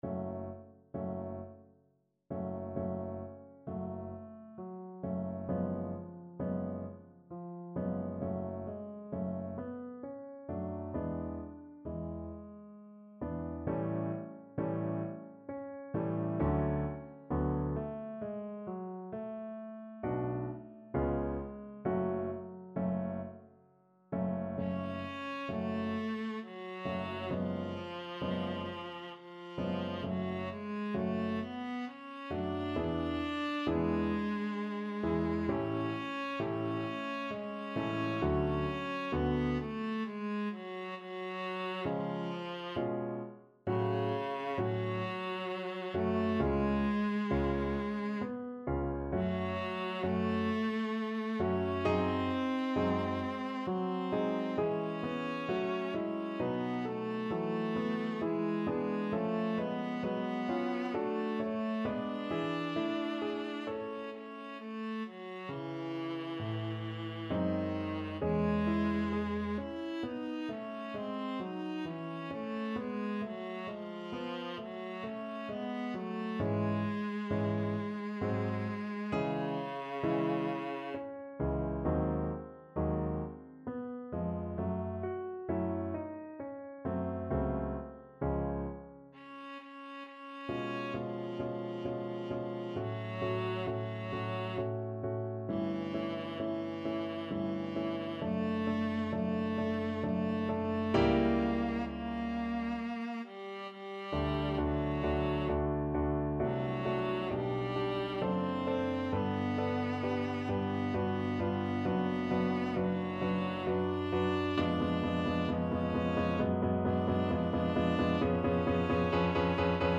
Classical
Viola version